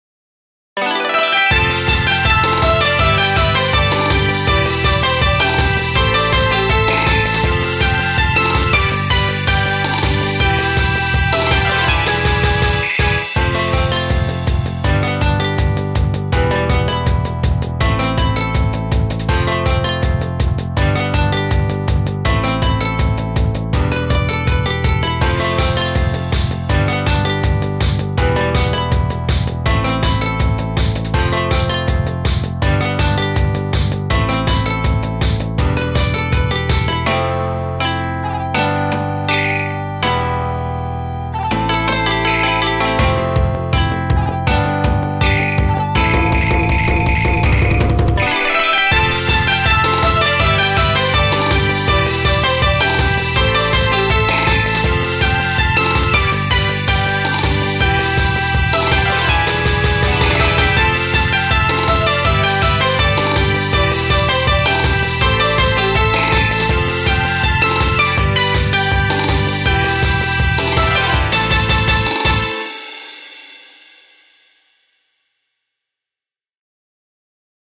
BGM
アップテンポショート